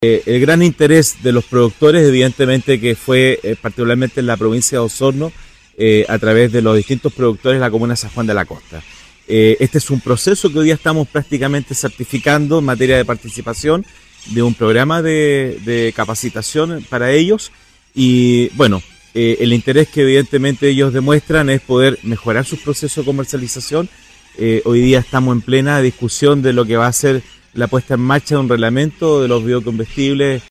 Por su parte, el alcalde de San Juan de la Costa, José Luis Muñoz, destacó que el interés que han demostrado los productores locales por mejorar sus procesos de comercialización es clave, en relación a la implementación de la Ley de Biocombustibles.